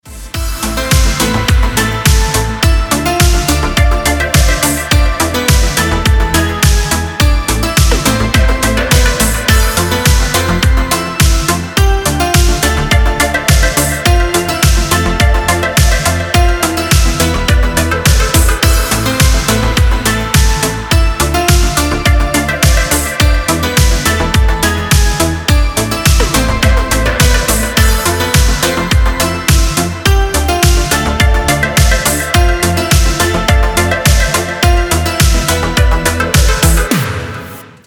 Категория: Шансон рингтоны